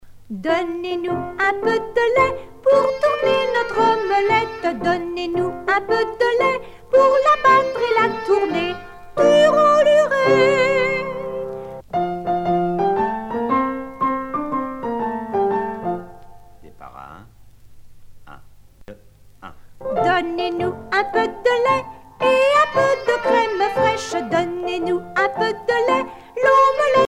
danse : rondes enfantines (autres)
Pièce musicale éditée